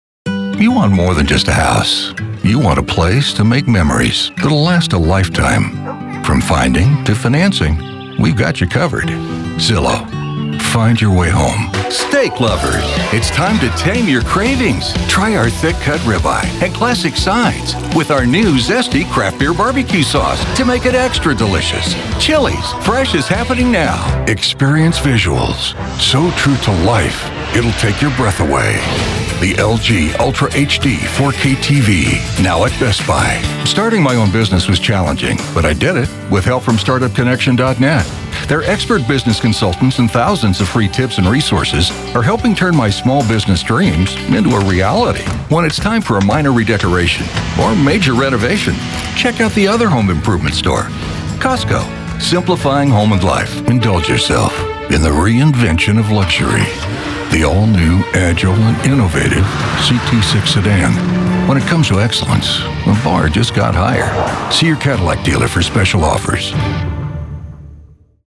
Commercial
Middle Aged
Senior
• I have a professional grade home studio